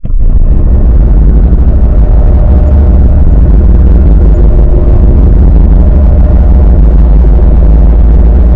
Tag: 循环 地狱 环境 背景 音乐 令人毛骨悚然 恐怖 空间 黑社会